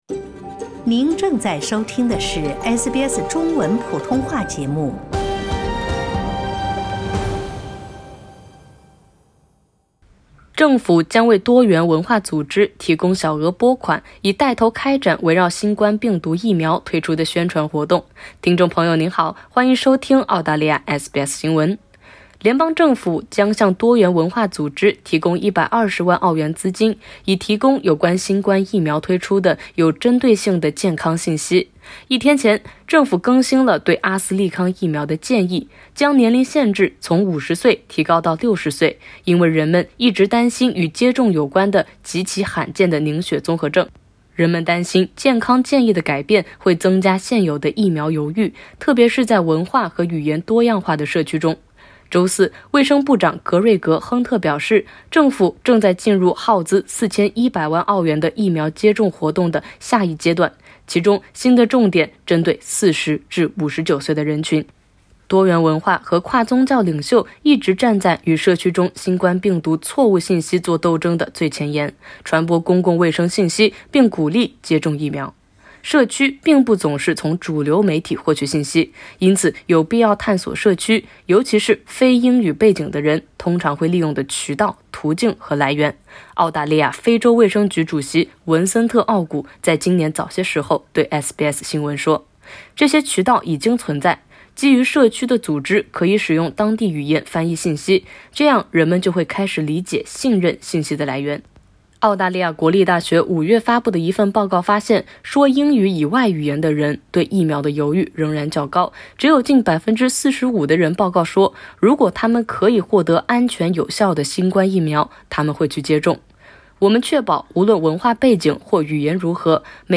联邦政府将向多元文化组织提供120万澳元资金，针对性解决“疫苗犹豫”。（点击上图收听报道）